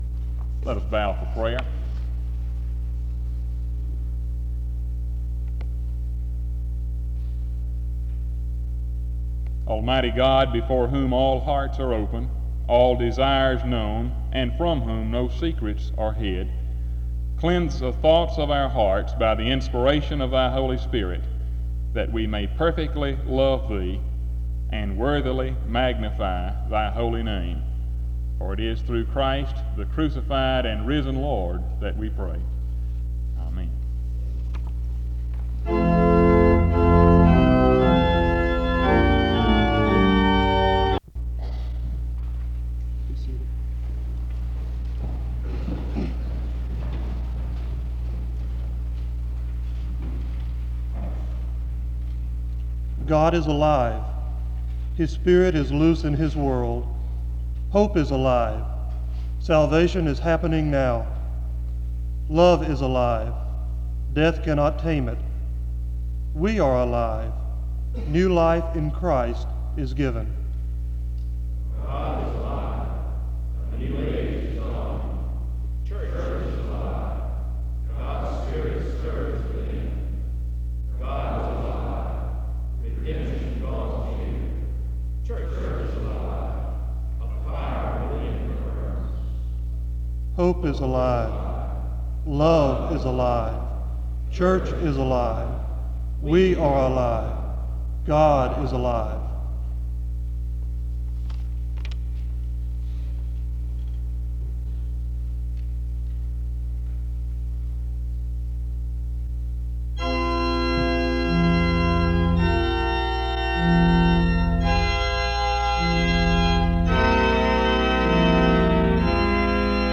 The service starts with an opening prayer from 0:00-0:33. A responsive reading takes place from 0:53-1:42. Music plays from 1:49-10:08.
He preaches on the cost of discipleship and the Lord’s table. This was a communion service. Music plays from 20:55-23:15.